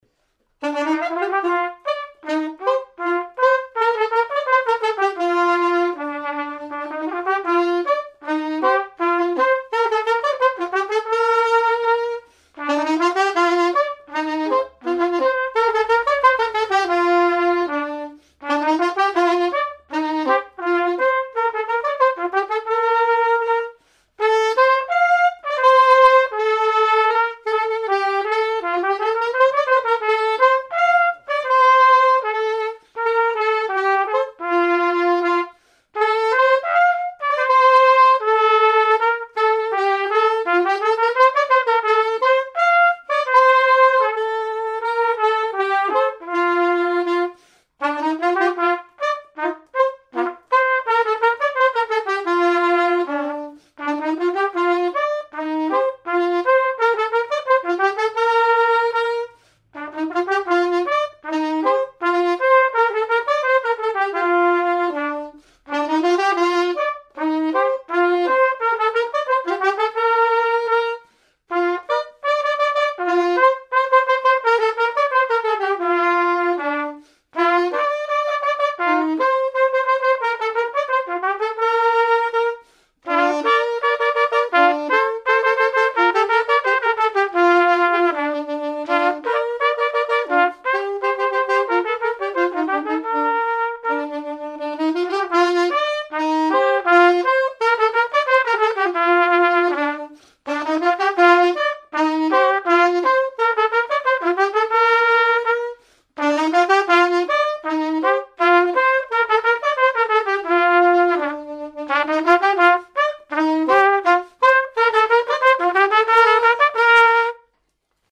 Marche de noce
Pièce musicale inédite